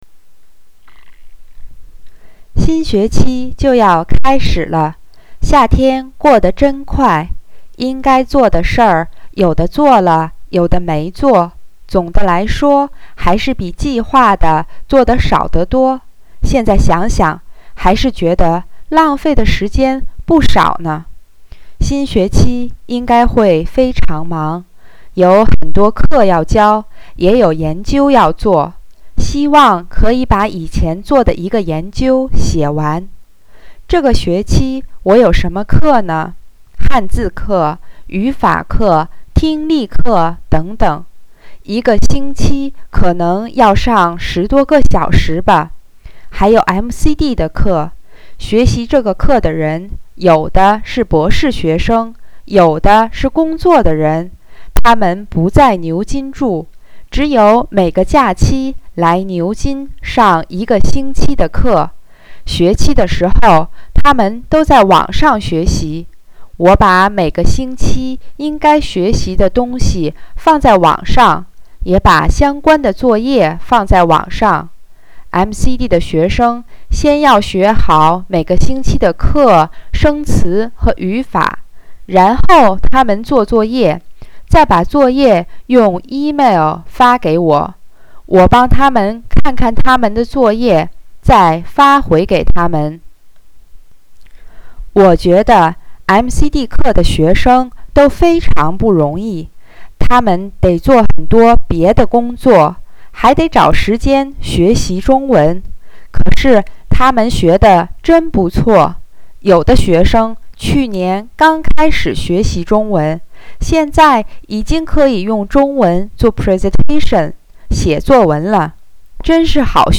Listening Comprehension
Listen (fast)                   New Words
mcd_intermediate_week-1_listening-fast.mp3